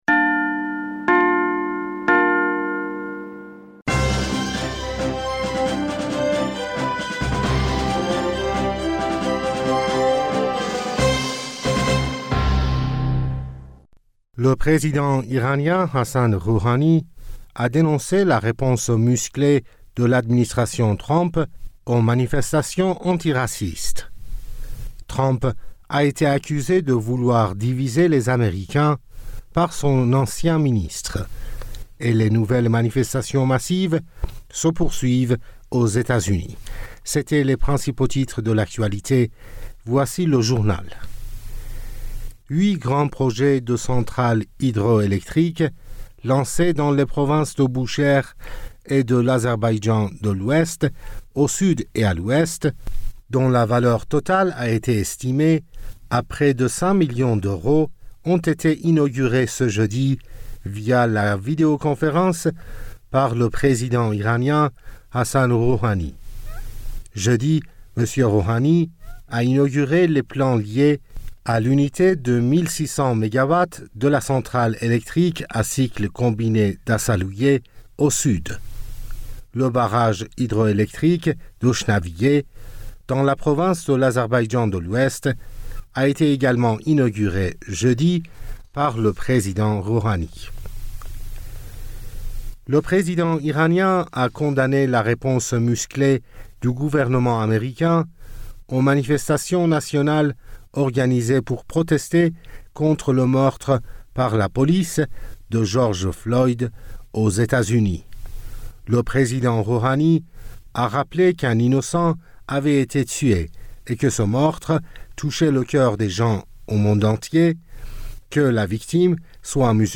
Bulletin d'information du 04 Juin 2020